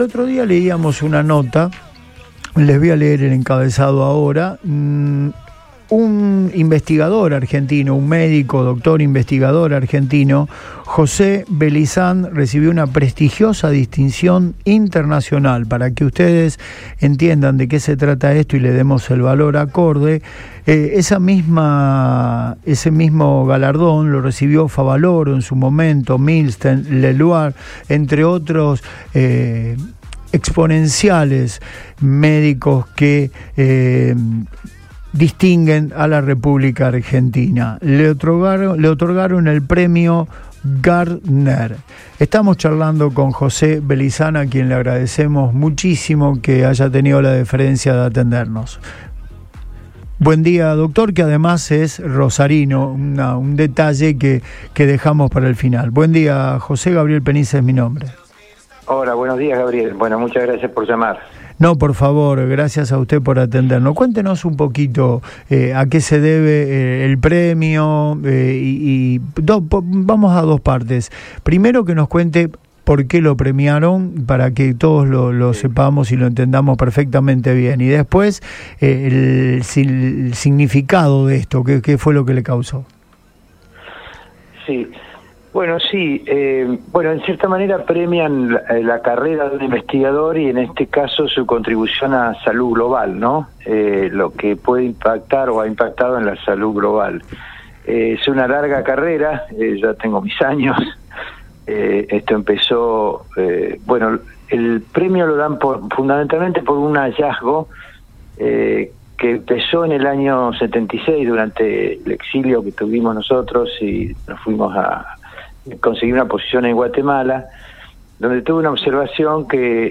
El profesional de la salud dialogó con